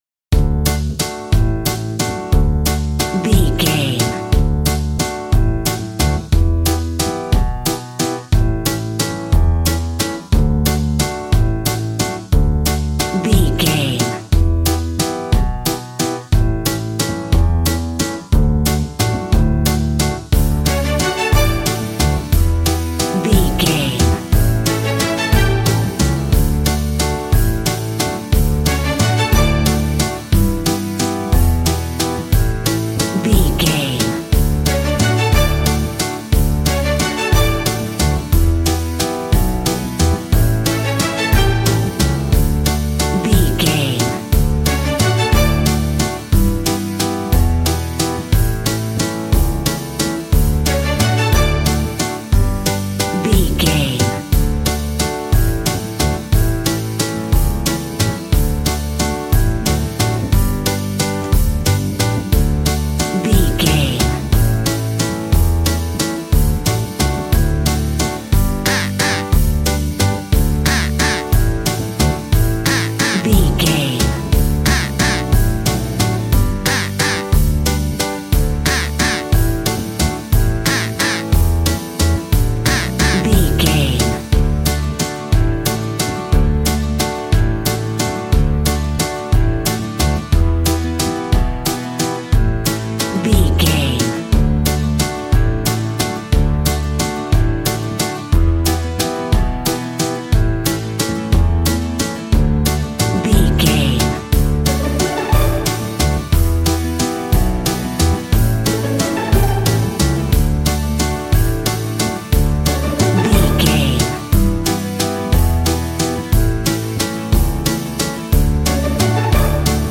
Upbeat, uptempo and exciting!
Ionian/Major
D
cheerful/happy
bouncy
electric piano
electric guitar
drum machine